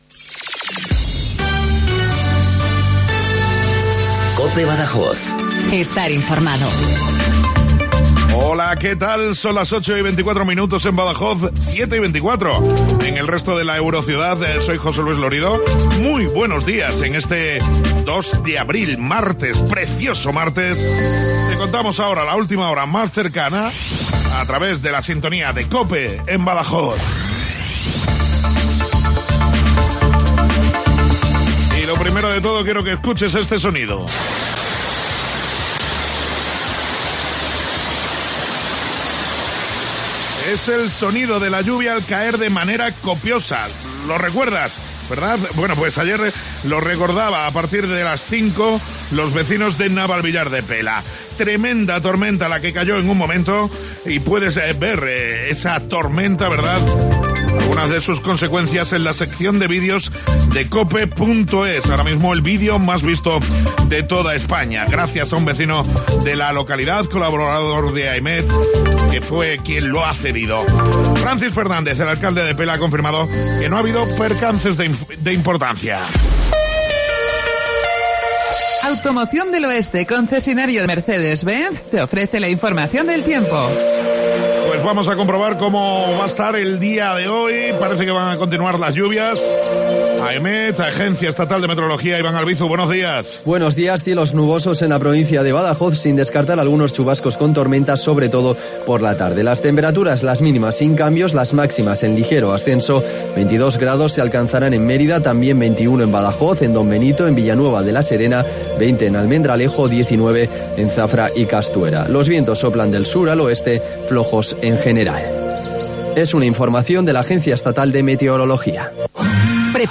INFORMATIVO LOCAL BADAJOZ 0824